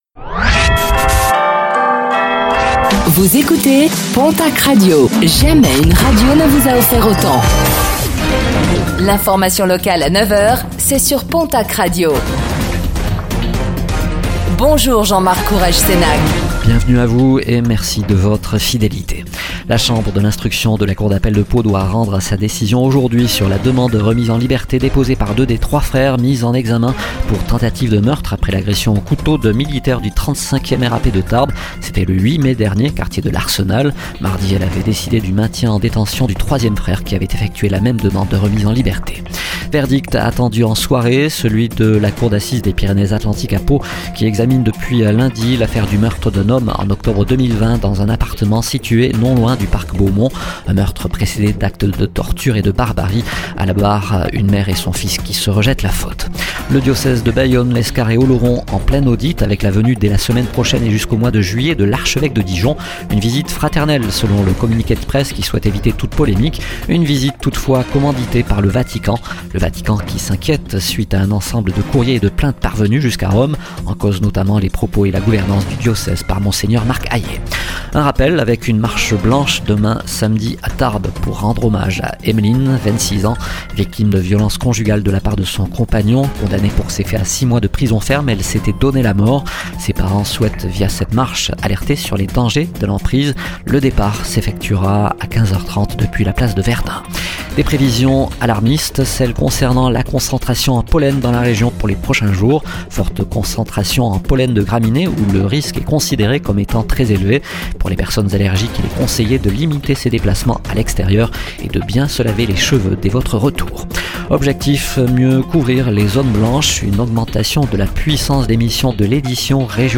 Réécoutez le flash d'information locale de ce vendredi 31 mai 2024